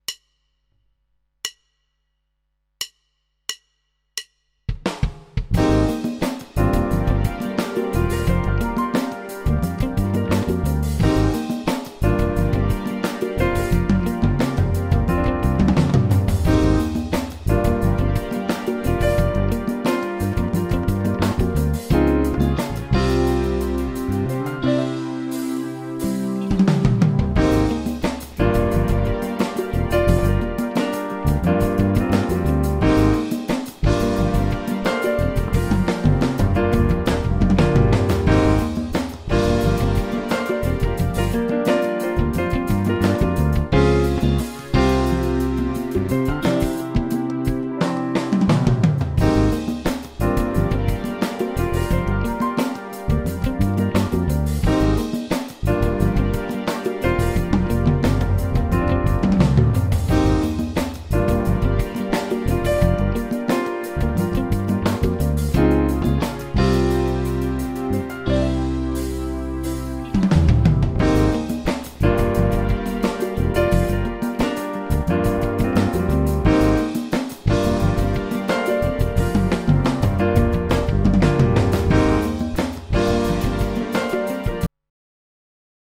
V ukázkách je slyšet různý setup výšky snímačů. 1,3 a 4 jsou na nový setup, který jsem si dělal při hraní ve zkušebně. 2 je původní, podle mě lepší na nahrávání do mixu.
Ukázka 3 - krkáč, clona 50%